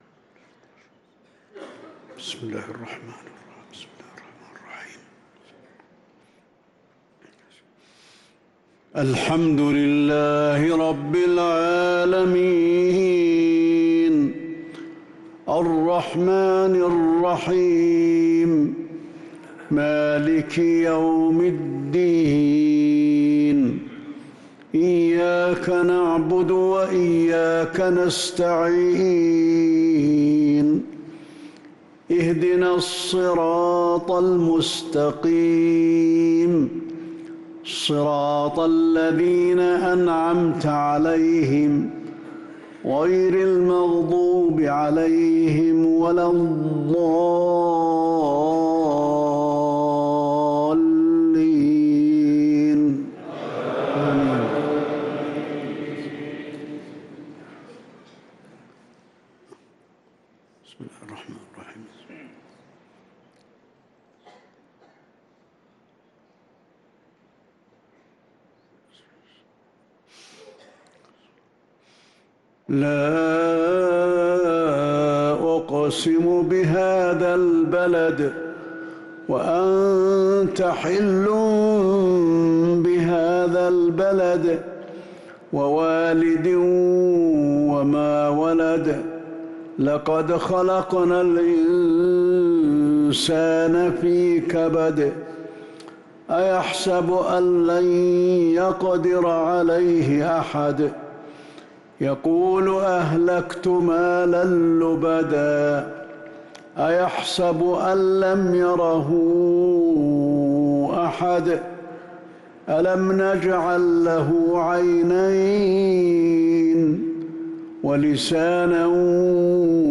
صلاة العشاء للقارئ علي الحذيفي 4 جمادي الآخر 1445 هـ